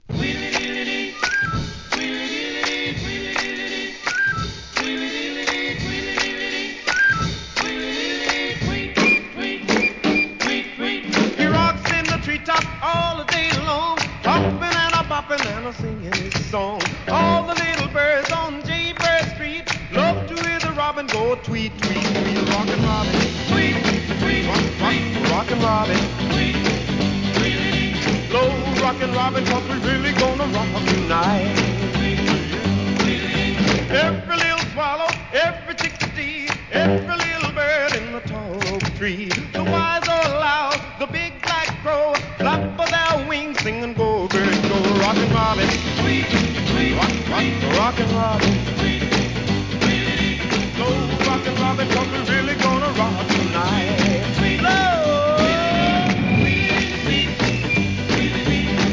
¥ 1,320 税込 関連カテゴリ SOUL/FUNK/etc...